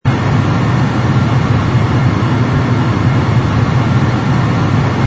engine_br_cruise_loop.wav